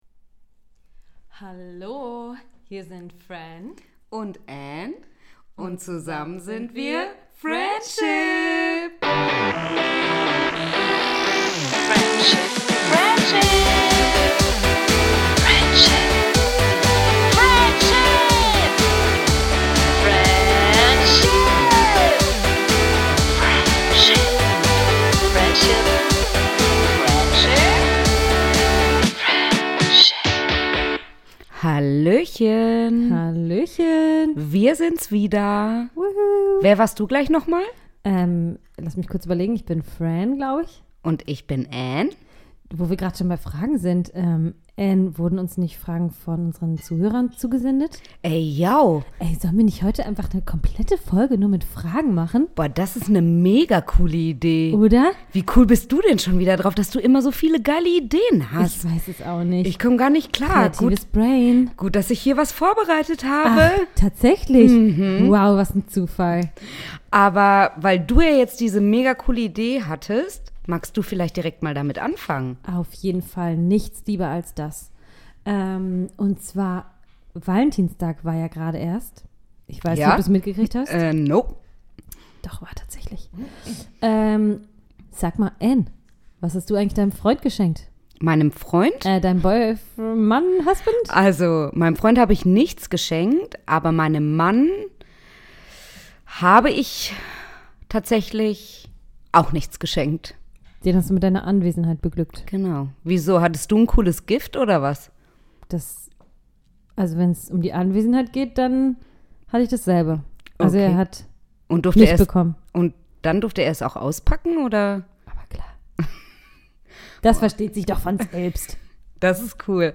Heute mal im Interview mit euch! Ihr stellt die Fragen und wir beantworten..